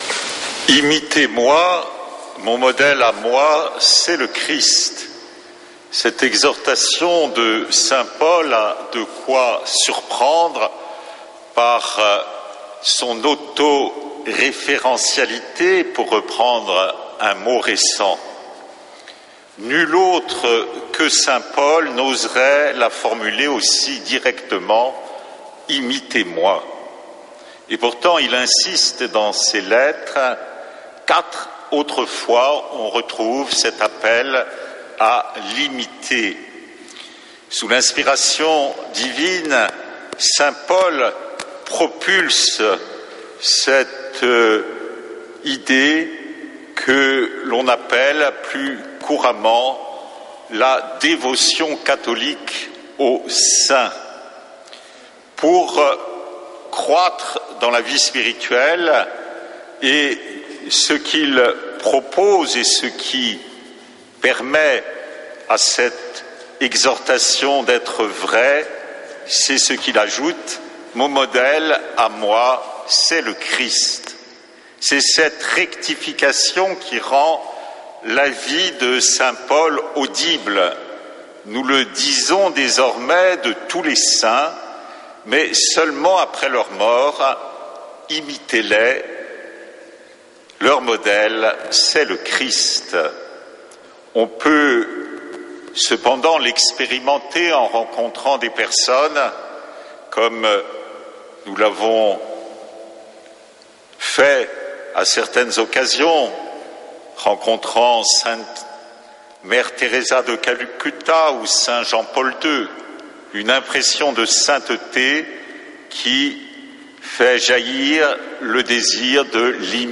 dimanche 1er février 2026 Messe depuis le couvent des Dominicains de Toulouse Durée 01 h 28 min